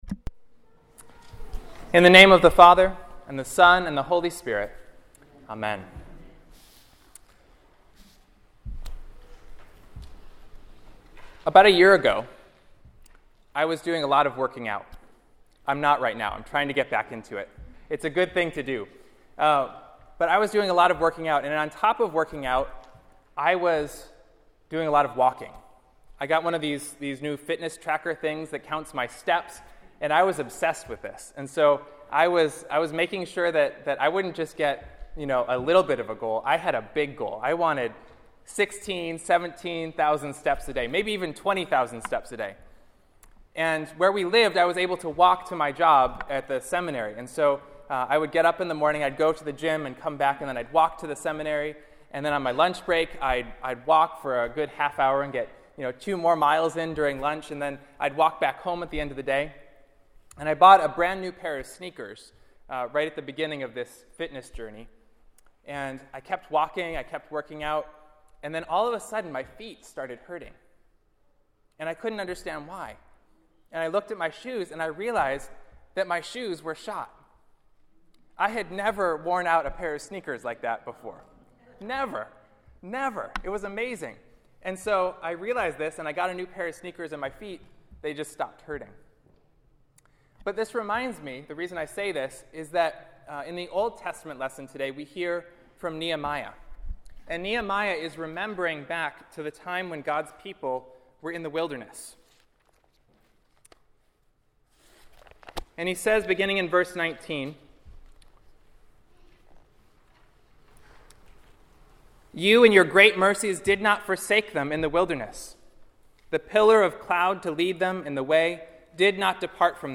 Year A – Pentecost 8-3 Nehemiah 9:16-21; Psalm 78:13-25; Romans 8:35-39; Matthew 14:13-21 Children’s Sermon Introduction Messianic Overtones Miraculous Provision God Still Provides Today Conclusion…